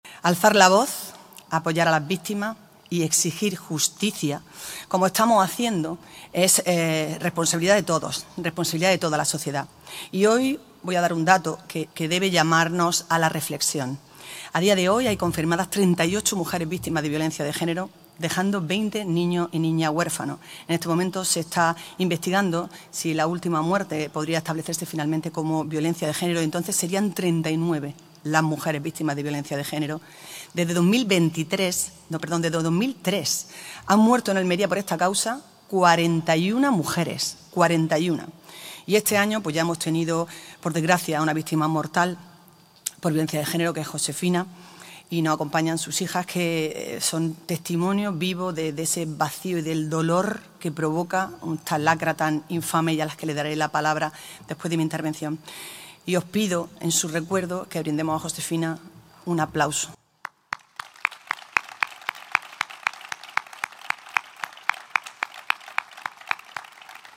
En la conmemoración del Día Internacional de la Eliminación de la Violencia Contra la Mujer, Almería se ha reunido en la Plaza de la Constitución para “visibilizar el rechazo de la sociedad almeriense al drama de la Violencia contra la Mujer.